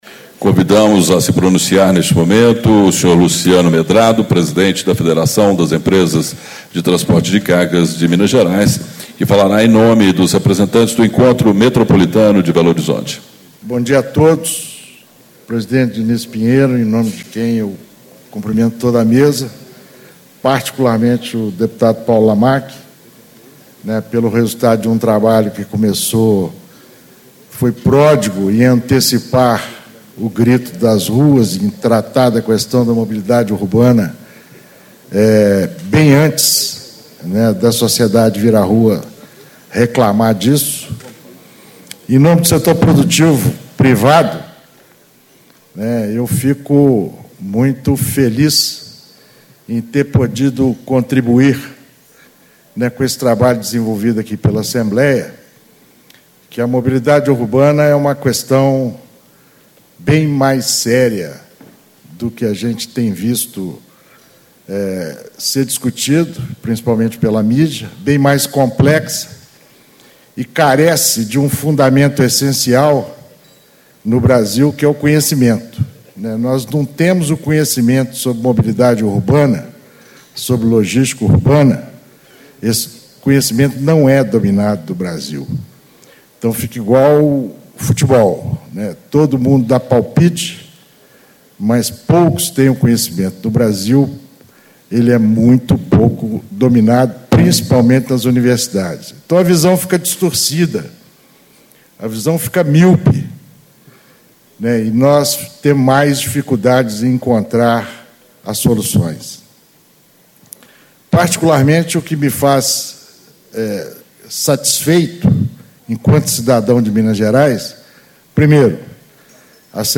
Encontro Estadual do Fórum Técnico Mobilidade Urbana - Construindo Cidades Inteligentes
Discursos e Palestras